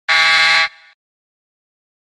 questions-pour-un-champion-effet-sonore-mauvaise-reponse.mp3